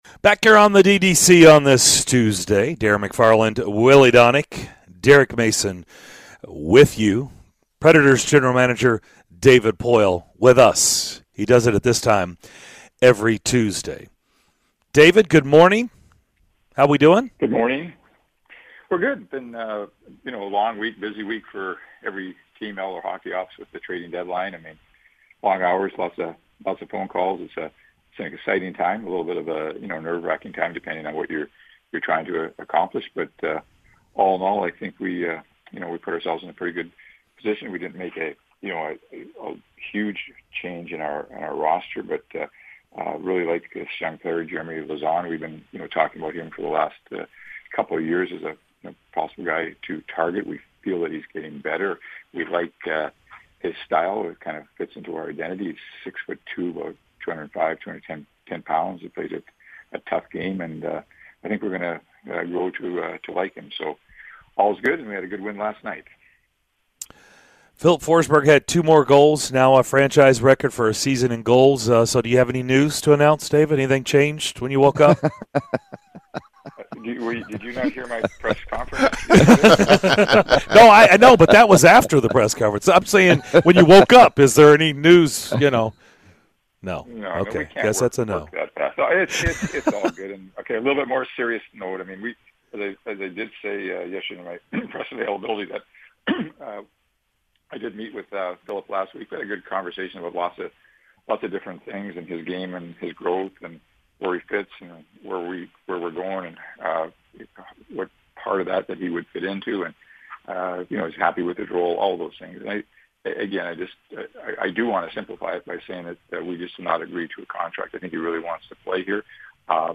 Nashville Predators GM David Poile joined the DDC to discuss the current contract negotiations with Filip Forsberg, the team's moves at the treading deadline and more during his weekly visit!